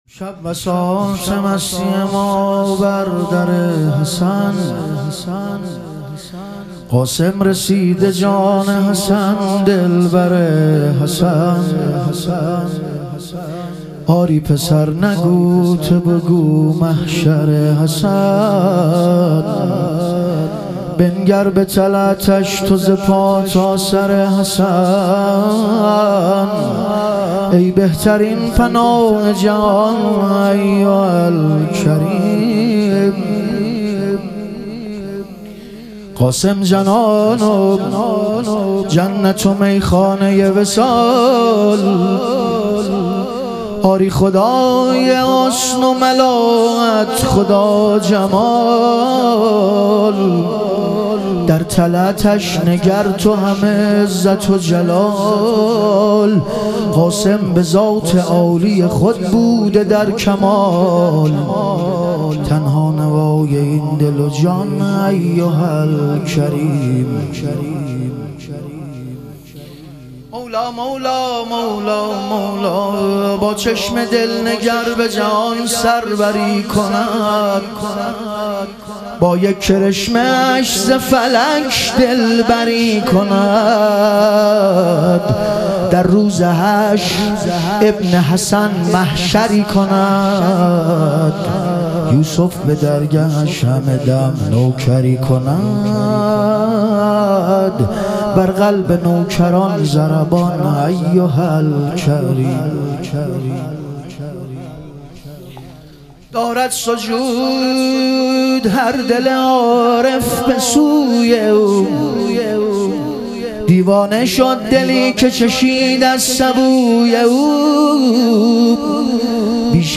ظهور وجود مقدس حضرت قاسم علیه السلام - مدح و رجز